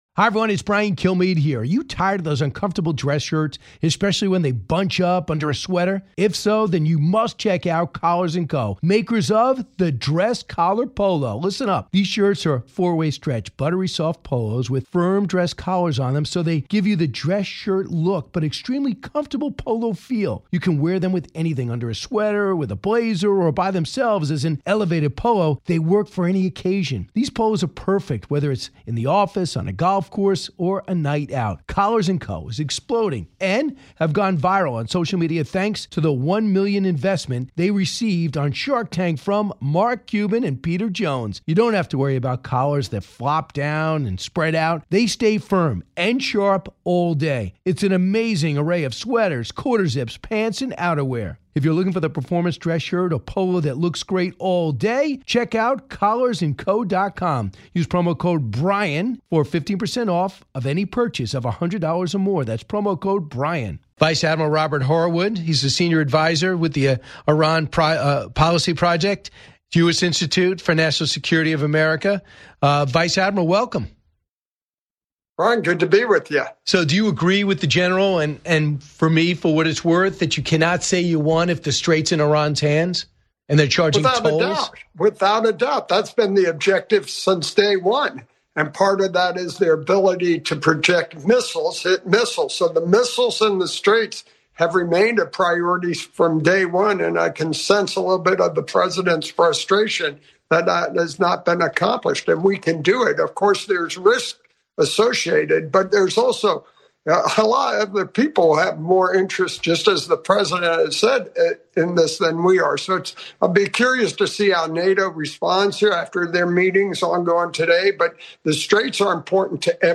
Retired Vice Admiral Robert Harward joins Brian Kilmeade to break down the latest escalation in the Iran conflict, including reports of a downed U.S. fighter jet and the high-stakes search and rescue operation for the pilot. Harward, a former Deputy Commander of U.S. Central Command, explains why Iran is clinging to the Strait of Hormuz as their final "lifeline" and what it will take for the U.S. to finally break the regime's control.